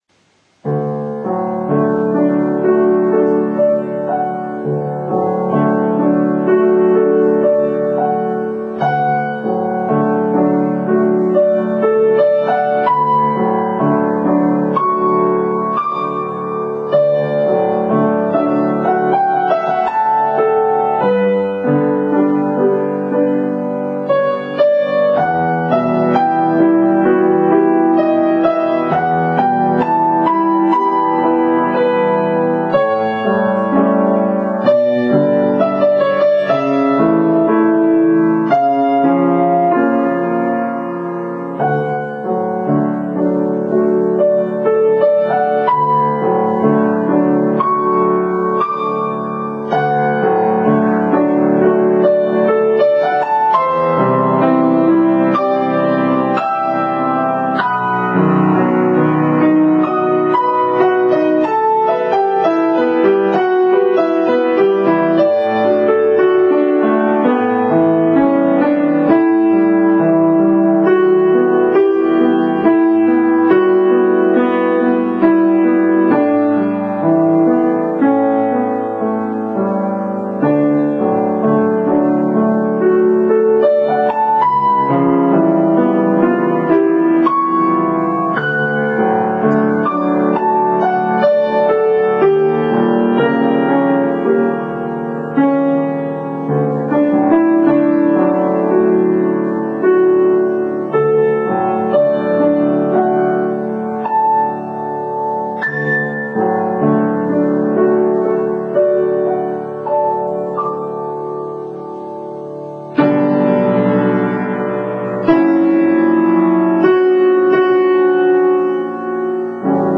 はかない美しさを感じるこの曲は、オペラ以外にもヴァイオリンの独奏などでもよく演奏され、BGMとしても使われています。今回はピアノのアレンジでお聴きください。